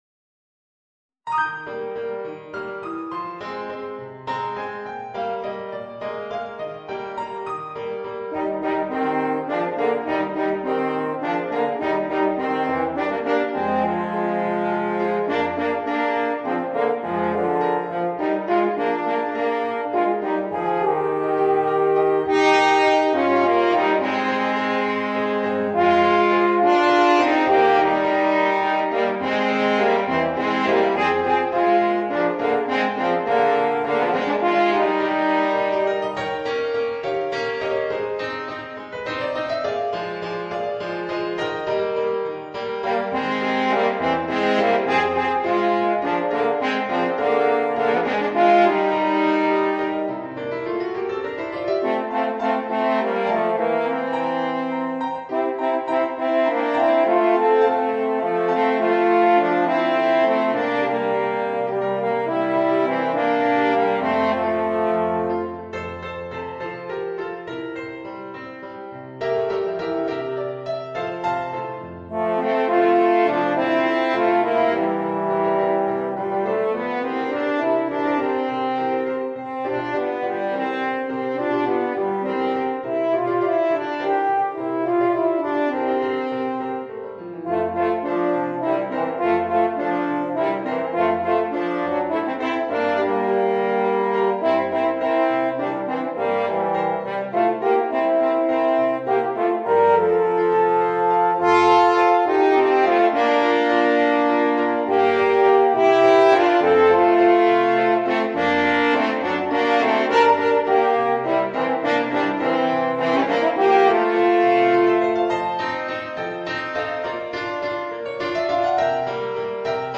2 Eb Horns and Piano